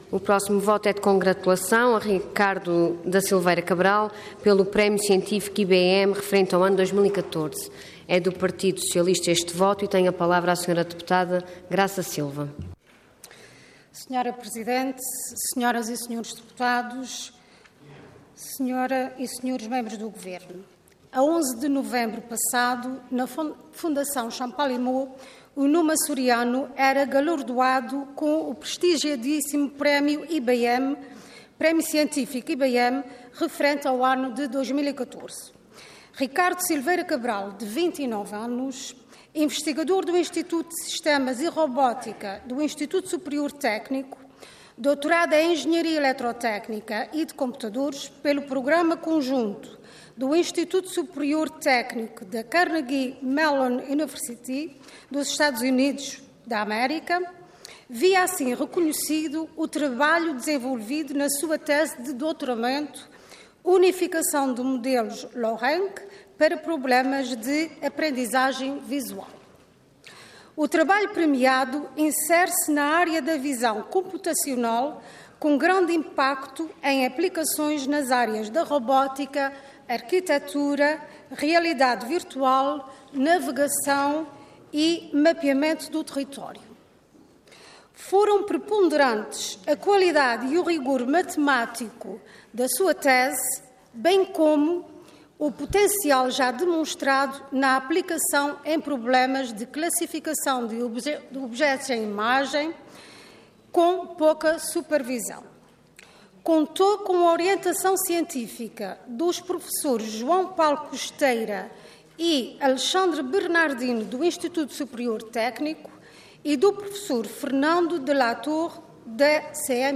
Intervenção Voto de Congratulação Orador Graça Silva Cargo Deputada Entidade PS